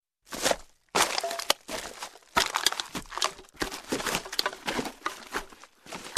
迅速踩住垃圾的靴子
Tag: 生活垃圾 步骤 移动